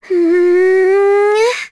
Lavril-Vox_Casting1.wav